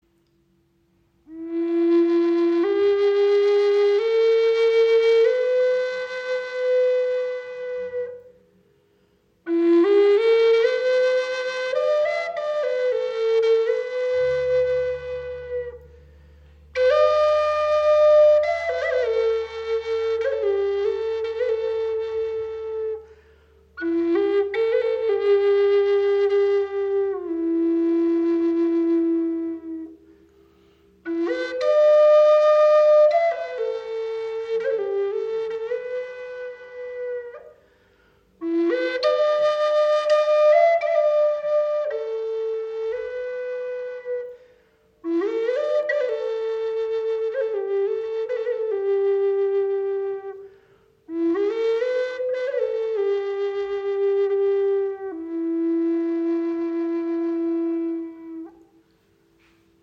Gebetsflöte in F - 432 Hz im Raven-Spirit WebShop • Raven Spirit
Klangbeispiel
Diese wundervolle Gebetsflöte ist auf F Moll in 432 Hz gestimmt und erzeugt einen warmen, tragenden Klang.